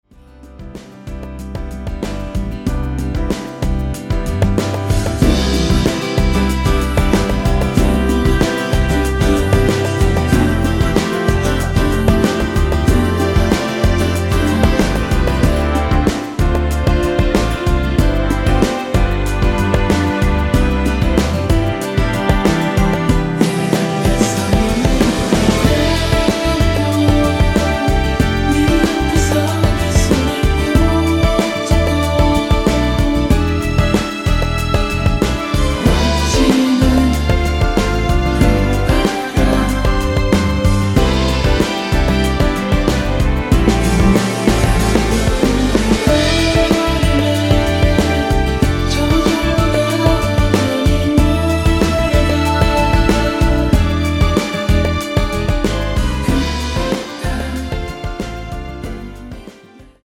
원키에서(-1) 내린 코러스 포함된 MR 입니다.(미리듣기 참조)
앞부분30초, 뒷부분30초씩 편집해서 올려 드리고 있습니다.